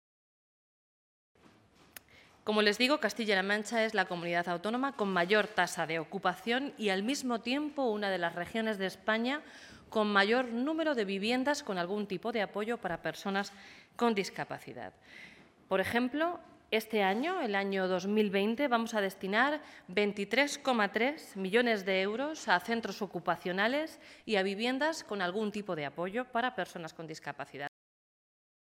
Consejería de Igualdad Martes, 18 Febrero 2020 - 1:00pm La portavoz regional ha informado hoy que el Gobierno de Castilla-La Mancha destinará 23,3 millones de euros en 2020 al mantenimiento de centros ocupacionales y viviendas con apoyo para personas con discapacidad. portavoz.gobierno_discapacidad2_180220.mp3 Descargar: Descargar